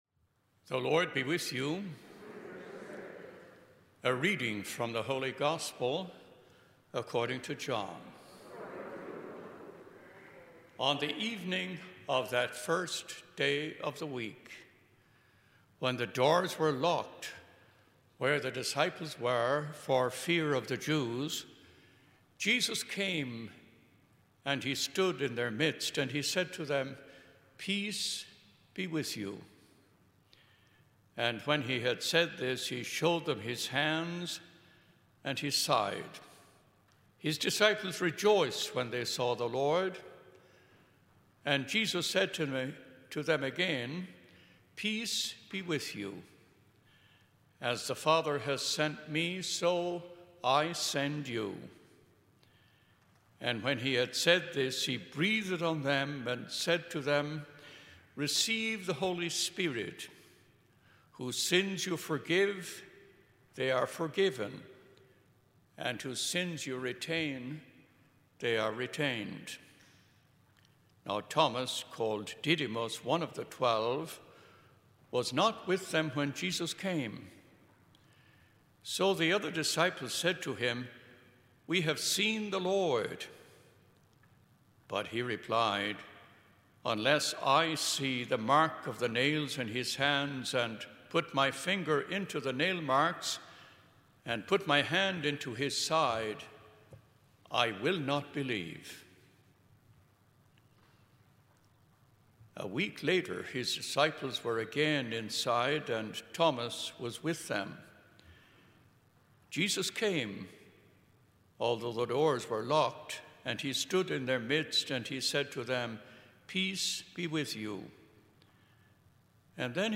Gospel and Homily Podcasts